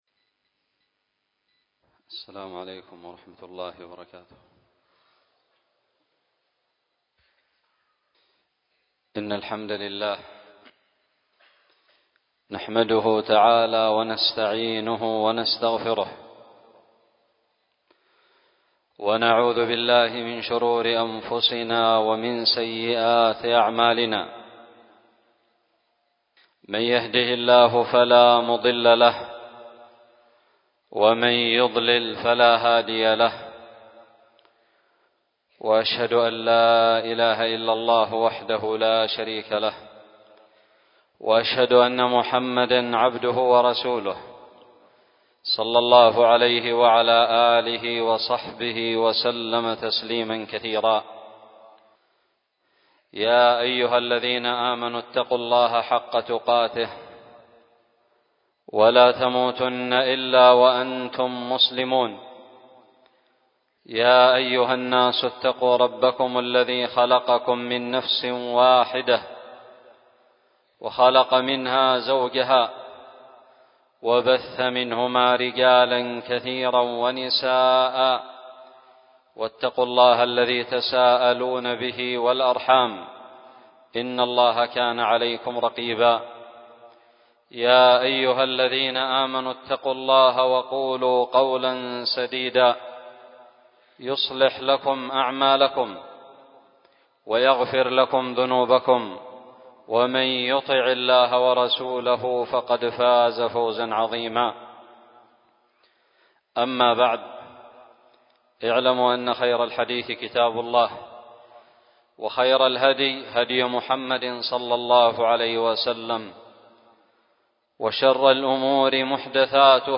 خطب الجمعة
ألقيت بدار الحديث السلفية للعلوم الشرعية بالضالع